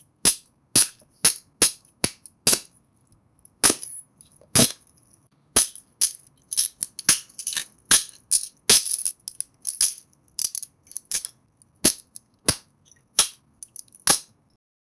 Royalty-free falling sound effects